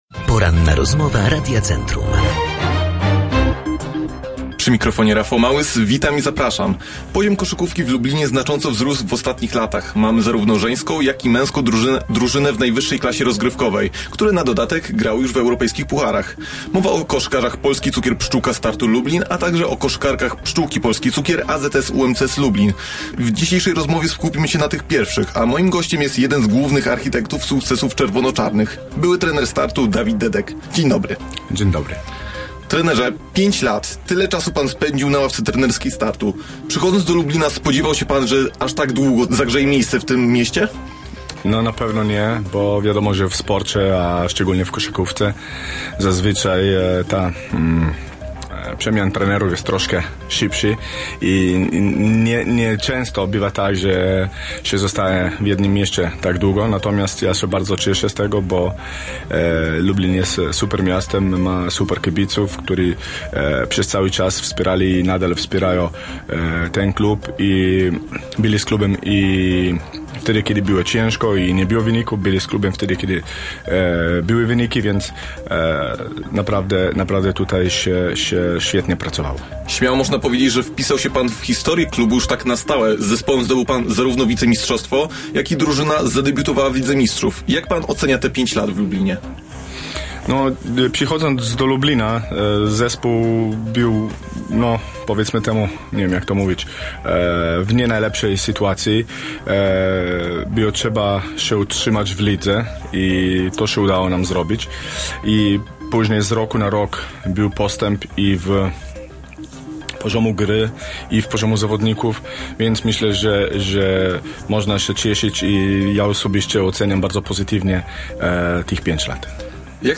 Rozmowa-po-edycji.mp3